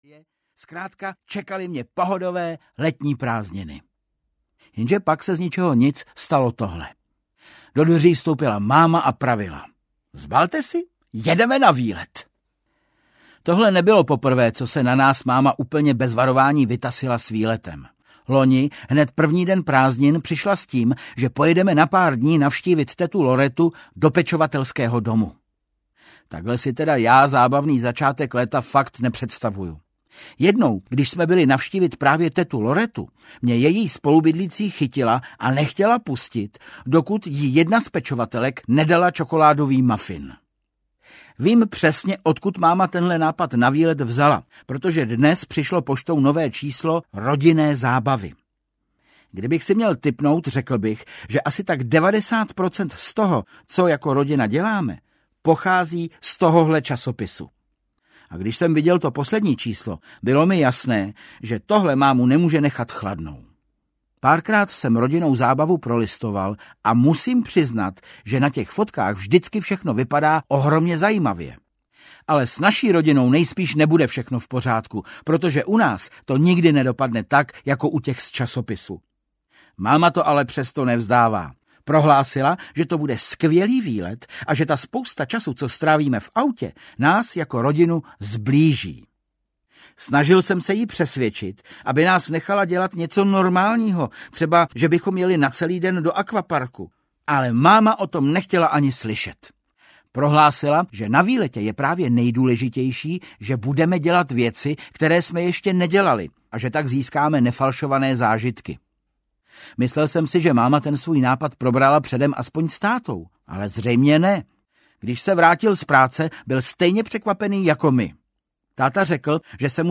Ukázka z knihy
• InterpretVáclav Kopta